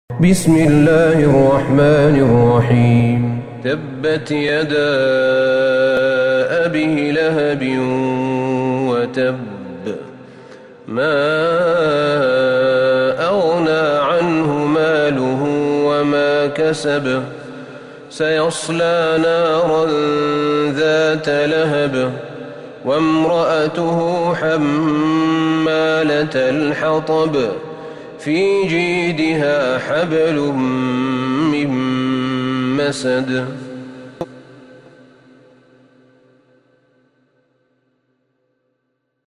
سورة المسد Surat Al-Masad > مصحف الشيخ أحمد بن طالب بن حميد من الحرم النبوي > المصحف - تلاوات الحرمين